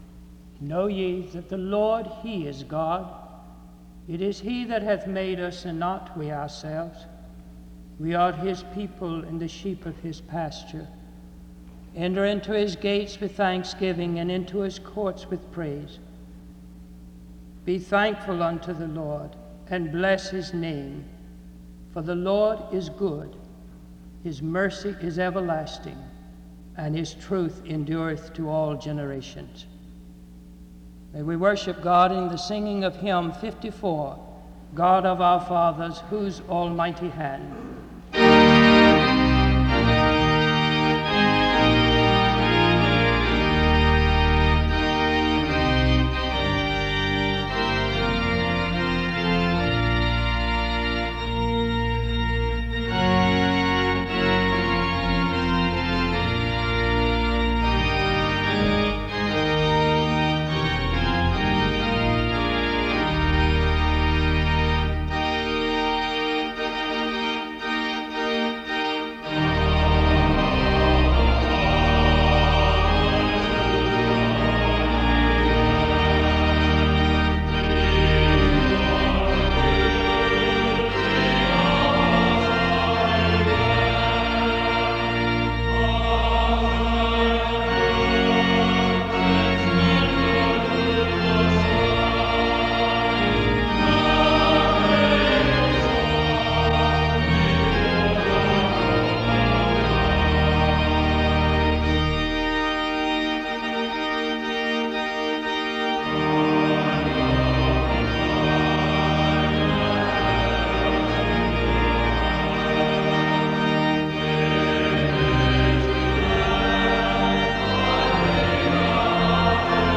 The service begins with a scripture reading from 0:00-0:33. Music plays from 0:36-3:52. A prayer is offered from 3:52-4:15.
Special music plays from 8:43-17:38.
The hymn, “Amazing Grace” is sung from 21:55-22:57.